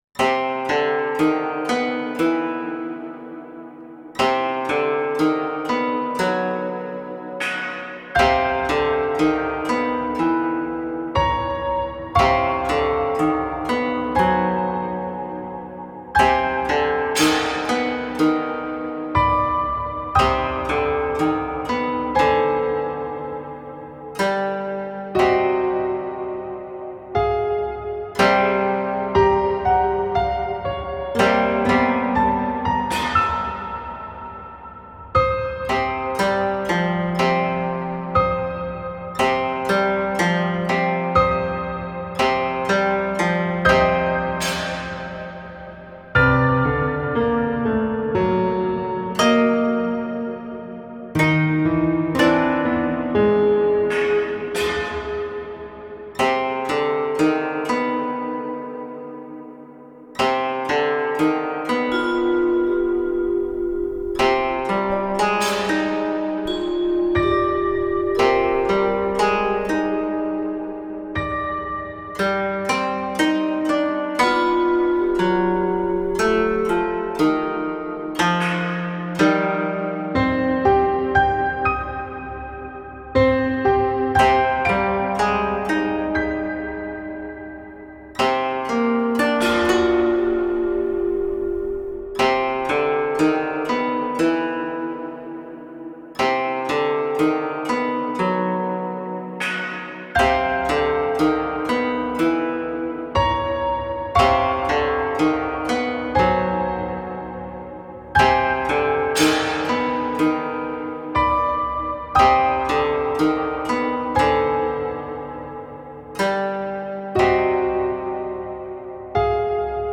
Horror Japanese style music No copyright music
Tag Dark , Horror/Scary , Koto , Piano
Enjoy the wet and scary sound of Koto and piano.